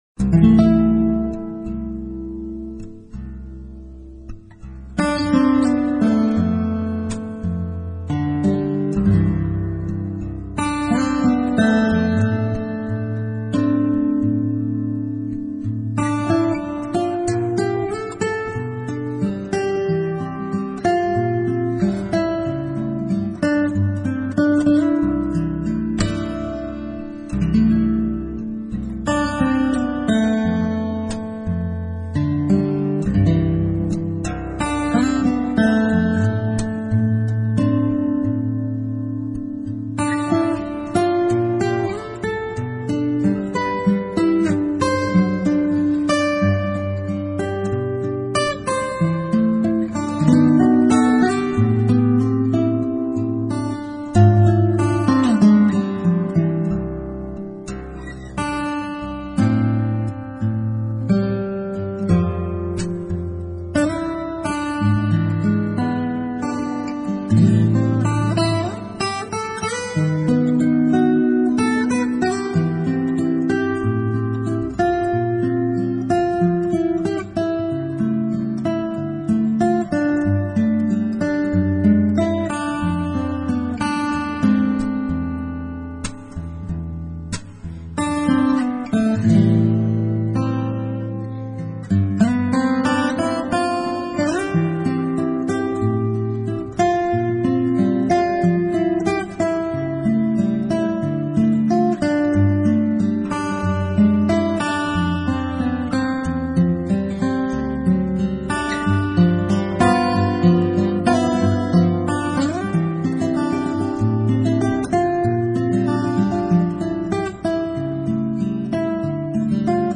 单纯的吉他表现，无需其他音乐的添料加香，呈现出最